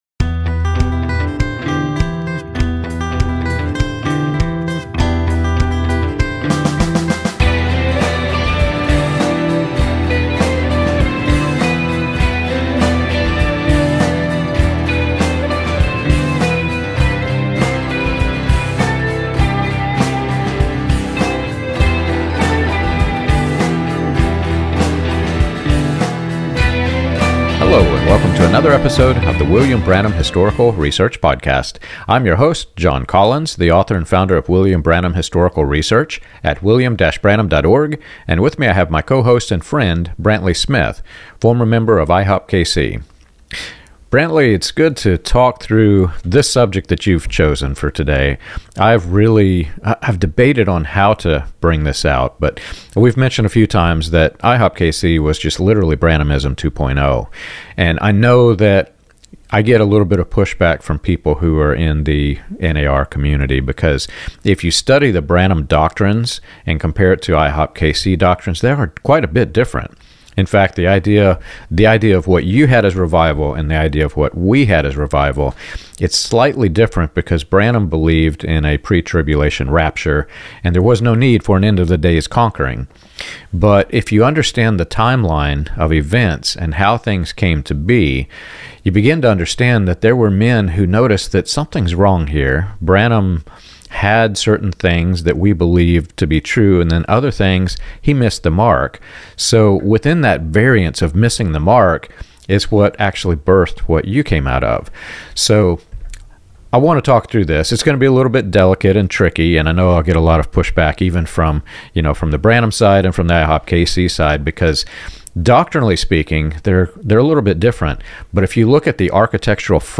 The conversation compares IHOPKC's culture to earlier streams connected to William Branham's movement, focusing less on theology and more on the repeating machinery: recruitment, platform access, unpaid labor, and the way prophetic claims get reshaped when predictions don't materialize.